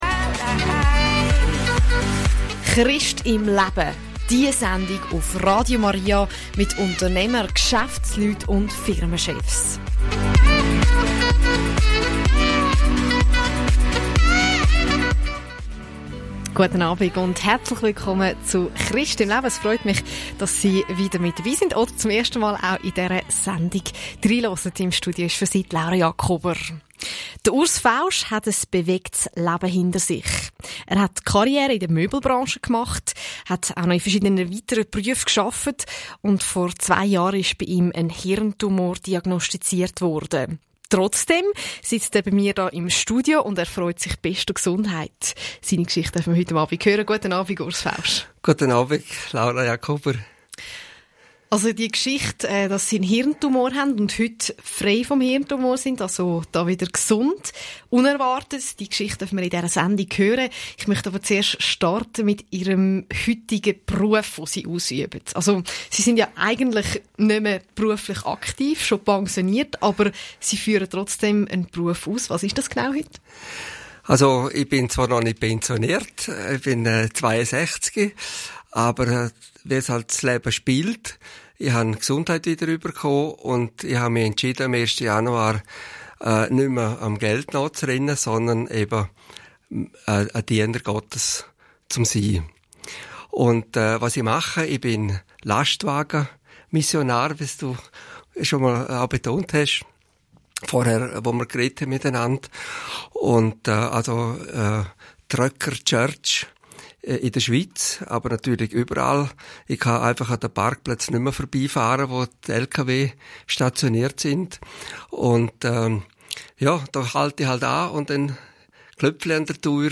Das Format «Christ im Leben» porträtiert Menschen, die ihren Alltag mit Gott verbringen. In rund 45 Minuten erzählte ich meine turbulente Geschichte mit Jesus. Der Weg bis zum Ziel führte über Möbel, Fruchtsäfte und Esoterik.